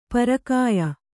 ♪ parakāya